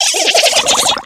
Cries
CROAGUNK.ogg